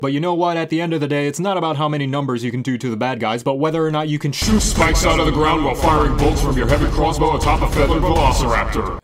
(DND) - Velociraptor
velociraptor.mp3